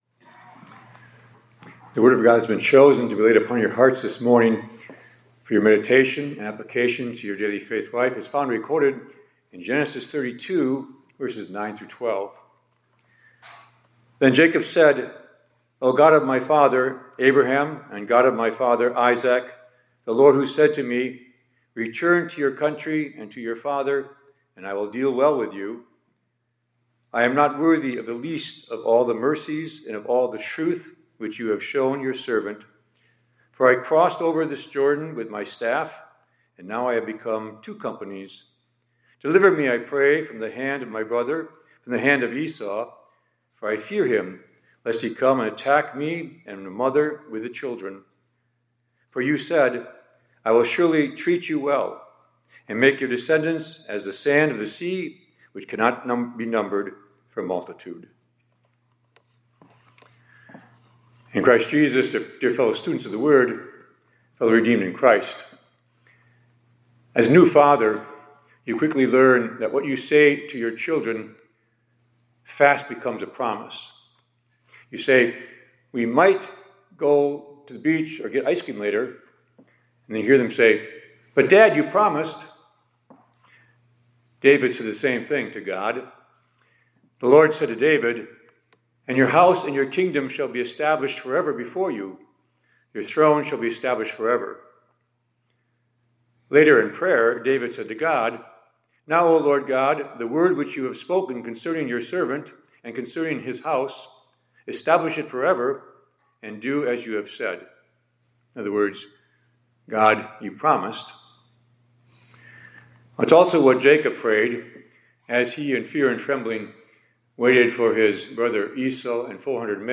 2026-02-09 ILC Chapel — “God, You Promised”
Hymn: WS 783, st. 1-3 : Jesus, Shepherd of the Sheep